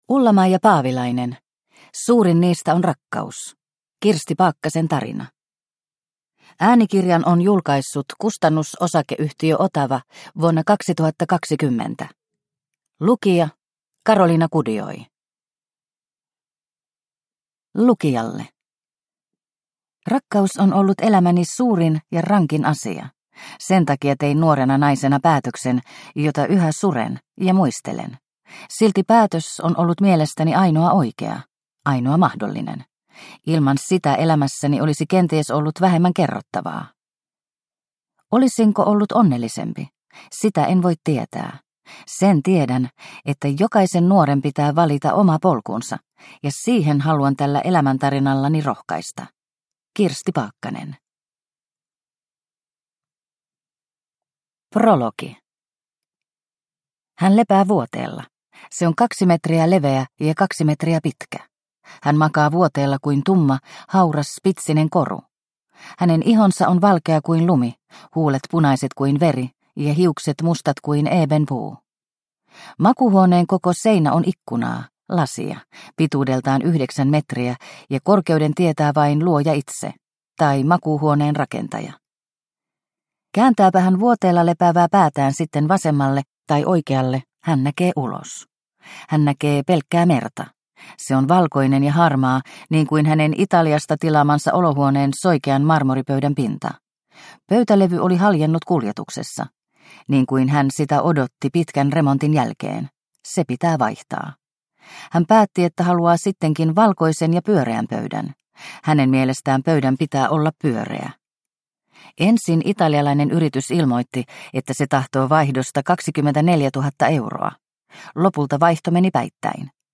Suurin niistä on rakkaus – Ljudbok – Laddas ner